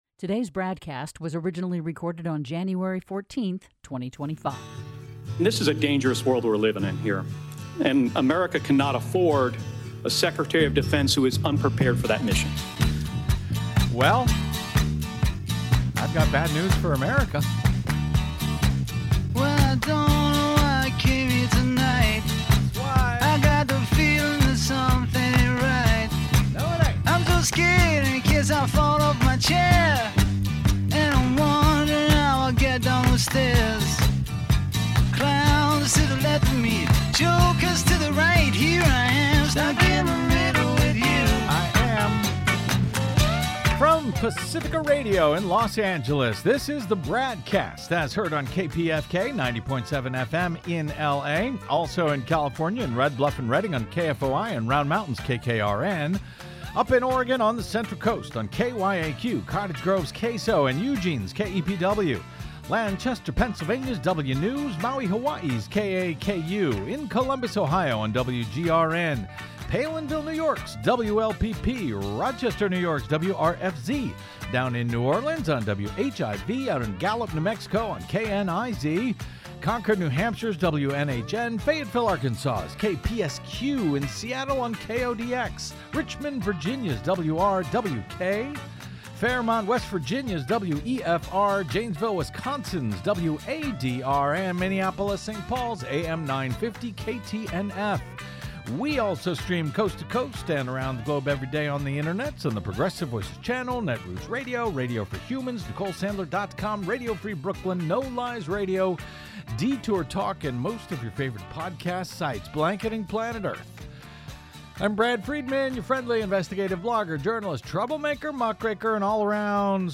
investigative interviews, analysis and commentary